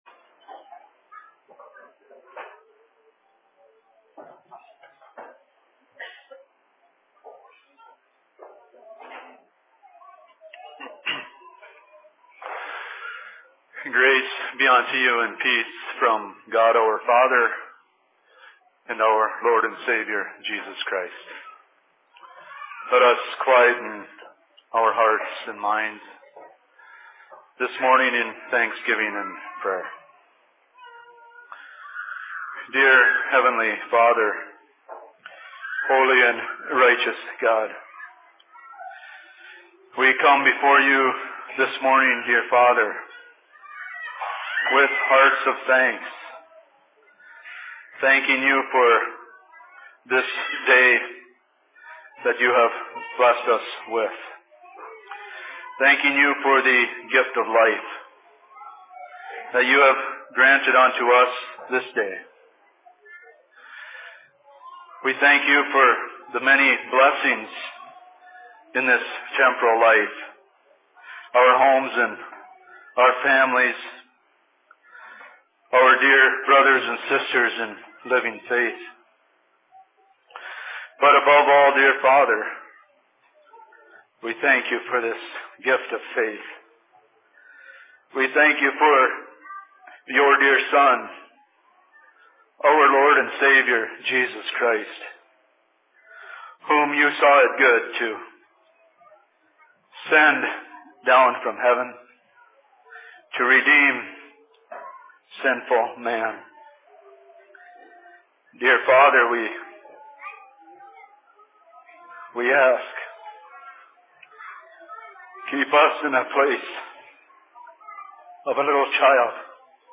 All Saints' Services/Sermon in Menahga 28.10.2012
Location: LLC Menahga